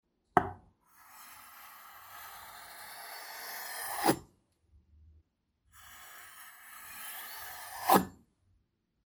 Звук движения лезвия — звук опасности или вполне мирный (как посмотреть)
Провели ножом по деревянной доске — два варианта